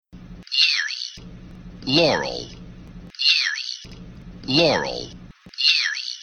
And here is that same file except that the 1st, 3rd, and 5th is put through a high-pass filter in which the cutoff frequency was about 1500 hertz, and the rolloff was 48 decibels:
That will make the "yanny" easier to hear. That should alternate between "yanny" and "laurel", although i often hear "yerry" or  "yammy" instead of "yanny".
yanny-laurel-high-pass-filter-on-1st-3rd-5th.mp3